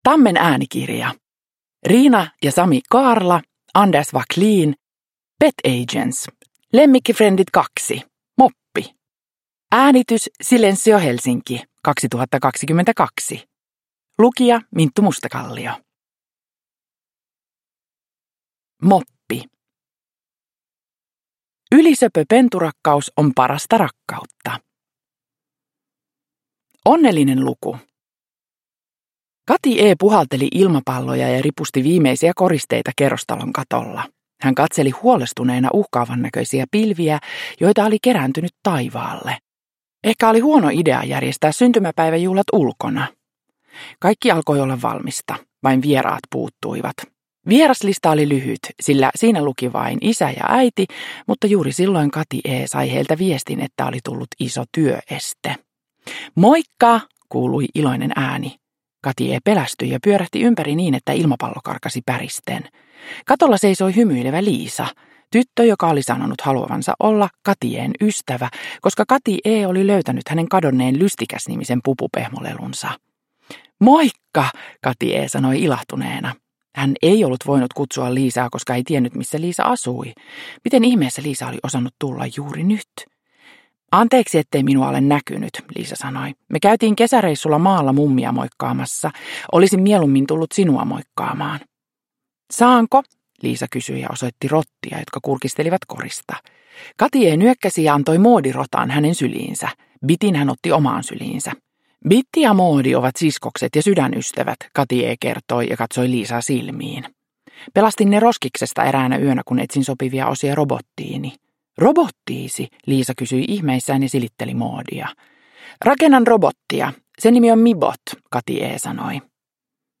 Moppi. Lemmikkifrendit 2 – Ljudbok – Laddas ner
Uppläsare: Minttu Mustakallio